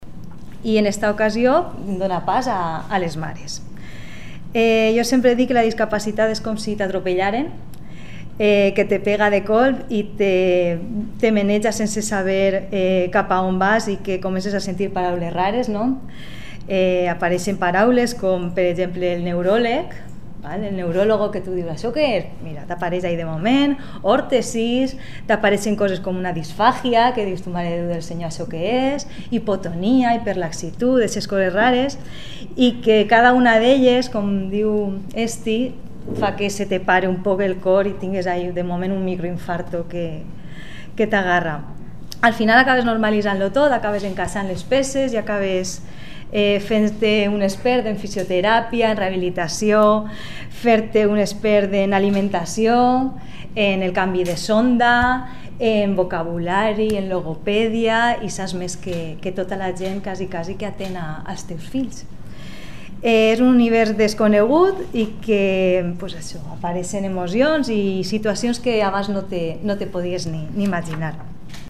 Con motivo del día internacional de la Diversidad Funcional que se conmemora hoy martes 3 de diciembre, la Asociación Iguals i Sense Través, ha llevado a cabo la presentación del libro El Renacer de los Girasoles.